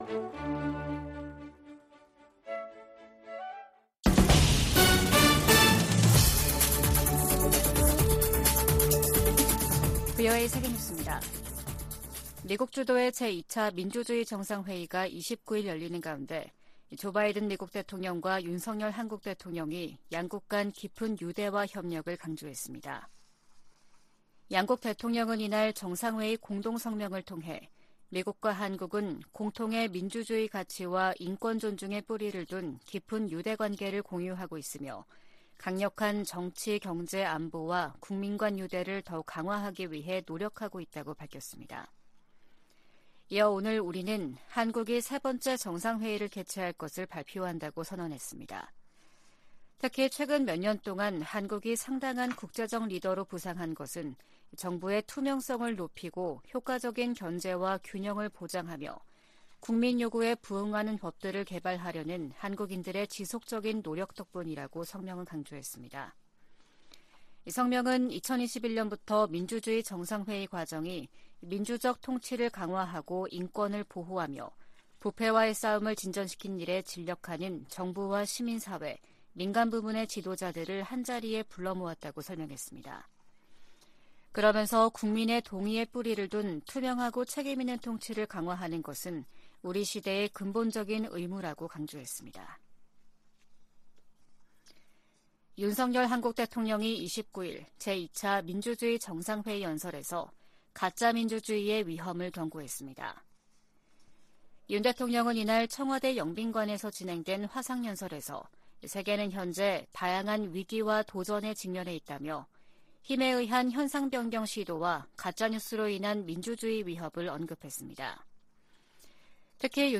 VOA 한국어 아침 뉴스 프로그램 '워싱턴 뉴스 광장' 2023년 3월 30일 방송입니다. 백악관은 북한의 전술핵탄두 공개에 국가 안보와 동맹의 보호를 위한 준비태세의 중요성을 강조했습니다. 국무부는 북한의 '핵 공중폭발 시험' 주장에 불안정을 야기하는 도발행위라고 비난했습니다. 전문가들은 북한이 핵탄두 소형화에 진전을 이룬 것으로 평가하면서 위력 확인을 위한 추가 실험 가능성이 있다고 내다봤습니다.